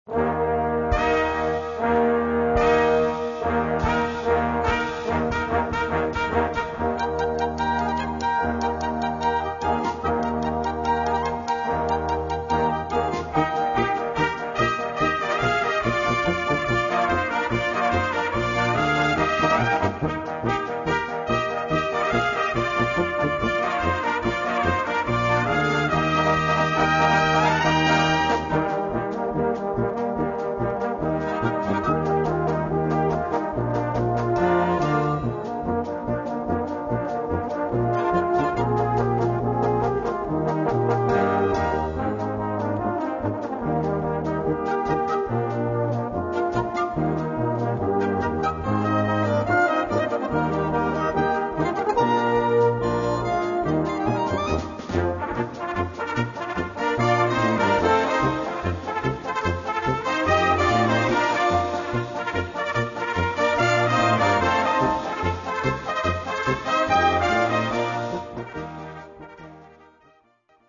Gattung: Solistische Bravourpolka
Besetzung: Blasorchester